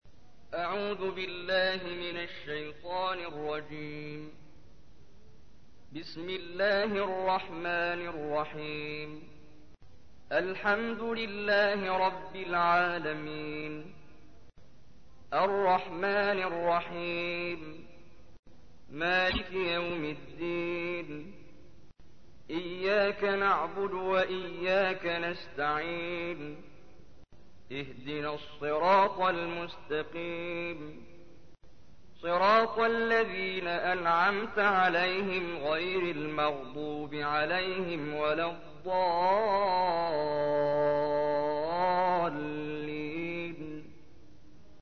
سورة الفاتحة / القارئ محمد جبريل / القرآن الكريم / موقع يا حسين